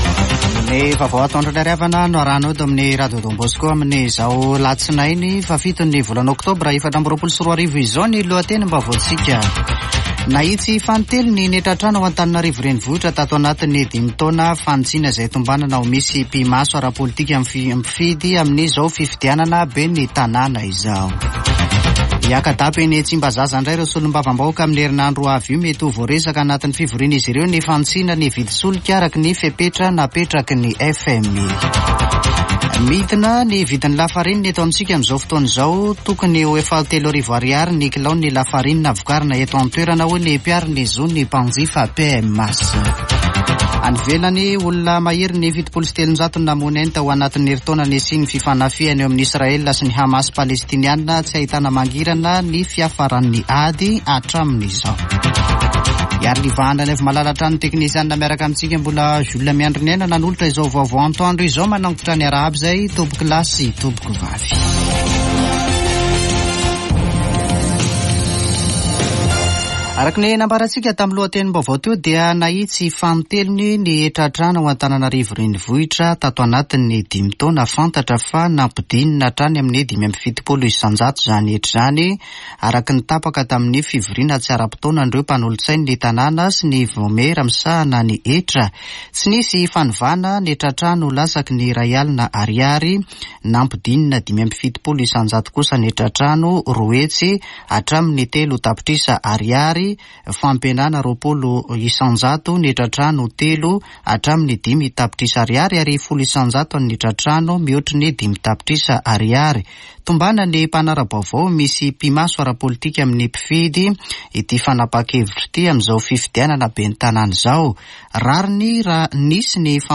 [Vaovao antoandro] Alatsinainy 7 Ôktôbra 2024